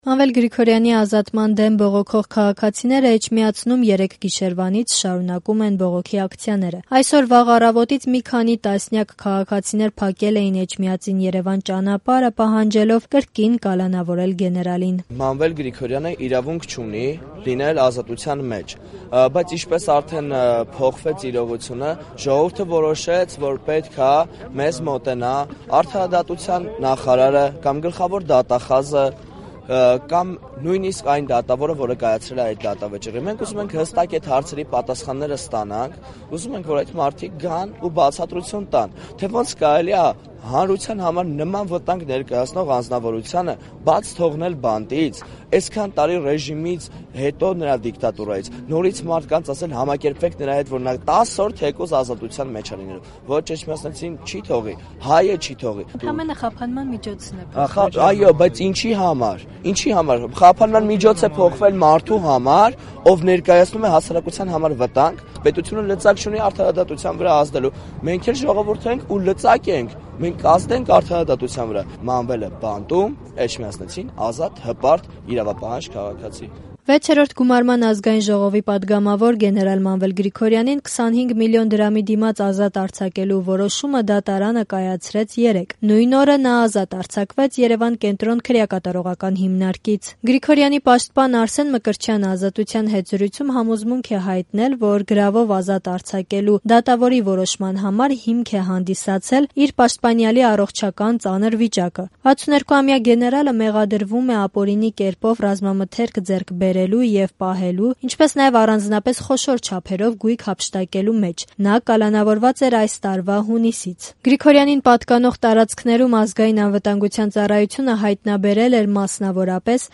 Բողոքի ակցիա․ «Մանվել Գրիգորյանը իրավունք չունի լինել ազատության մեջ»
Ռեպորտաժներ